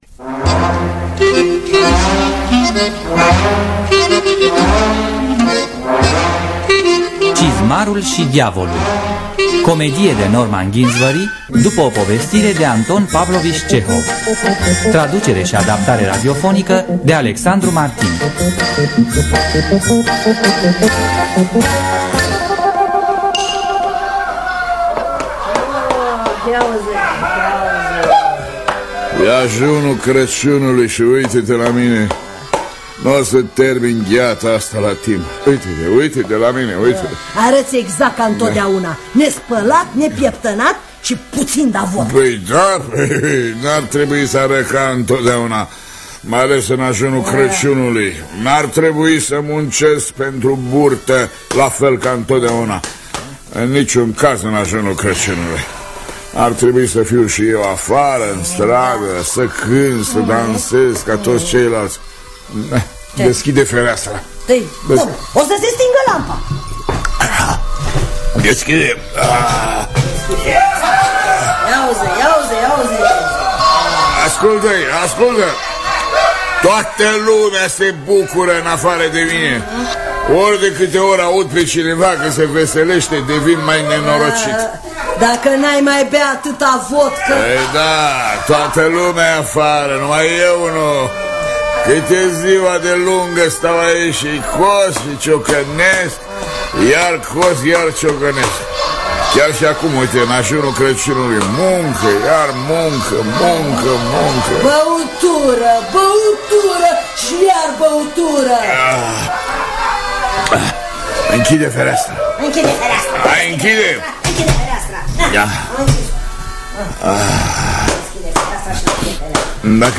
Norman Ginsbury – Cizmarul Si Diavolul (1990) – Teatru Radiofonic Online